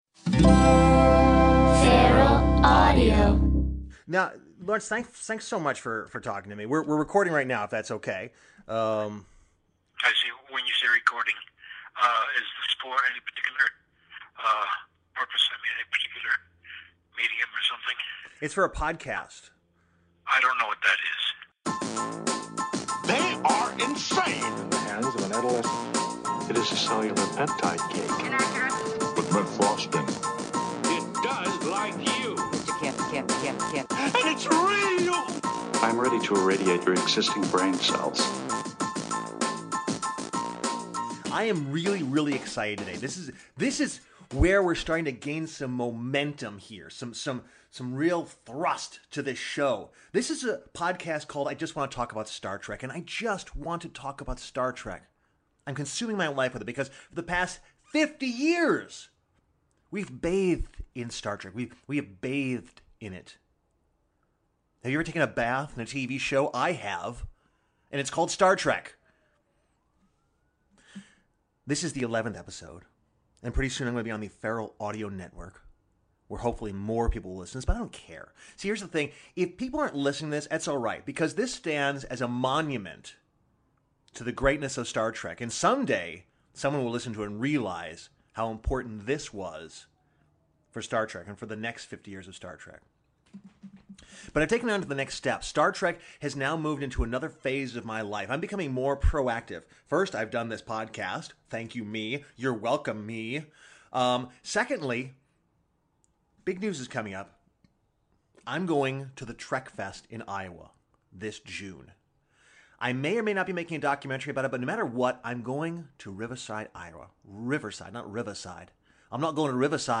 Then he puts a call into TOS actor Lawrence Montainge ('Decius' from "The Balance of Terror" and 'Stonn' from "Amok Time") and talks to him about his rivalry with Leonard Nimoy.